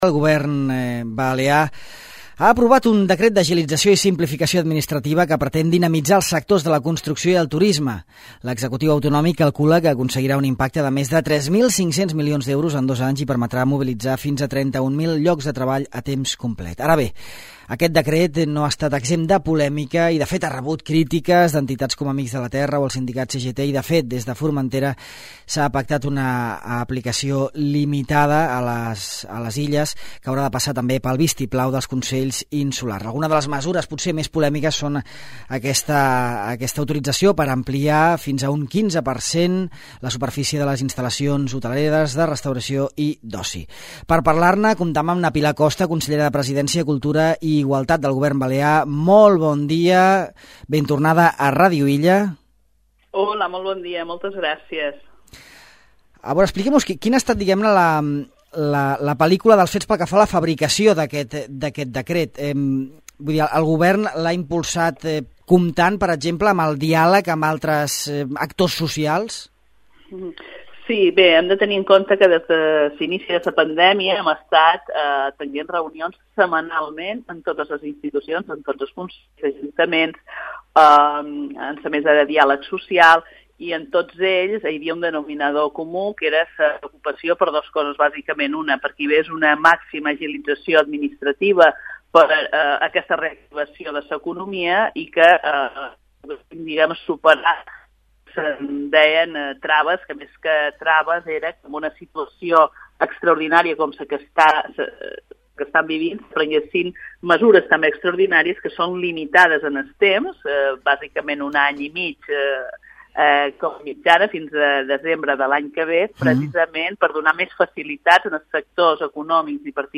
En parlam amb na Pilar Costa, consellera de Presidència, Cultura i Igualtat.